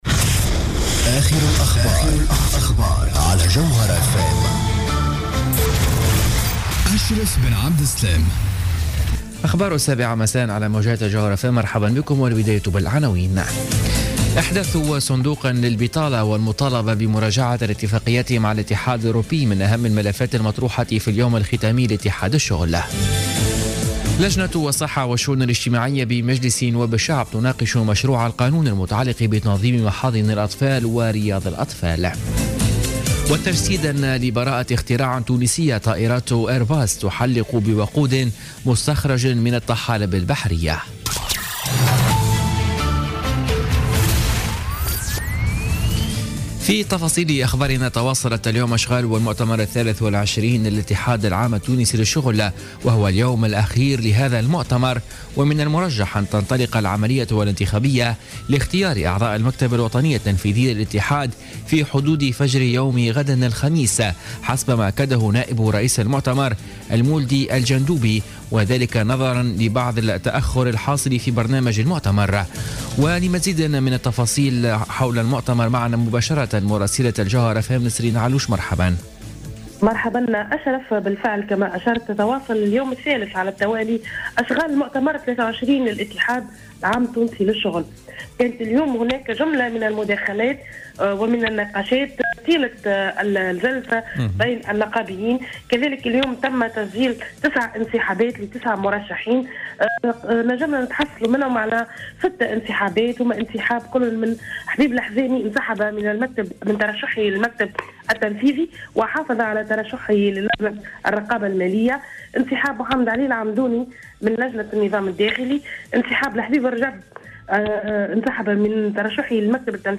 نشرة أخبار السابعة مساء ليوم الأربعاء 25 جانفي 2017